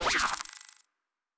pgs/Assets/Audio/Sci-Fi Sounds/Electric/Device 2 Stop.wav at master
Device 2 Stop.wav